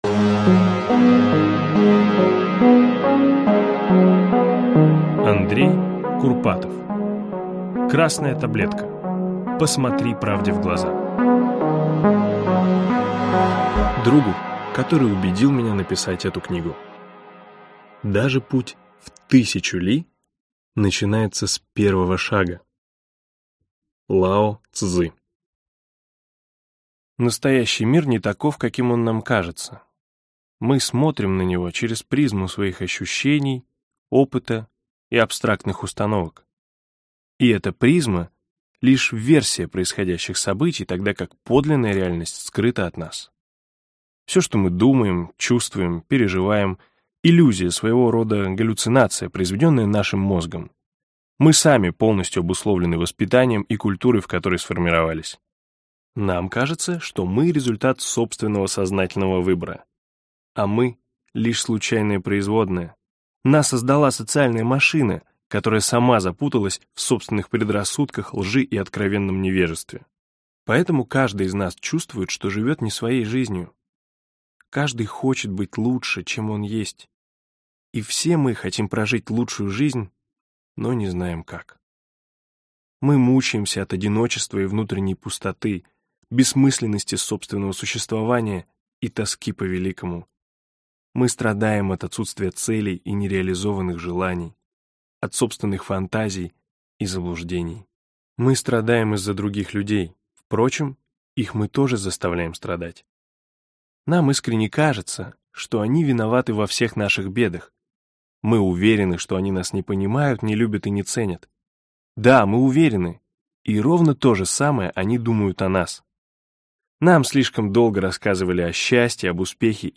Аудиокнига Красная таблетка. Посмотри правде в глаза | Библиотека аудиокниг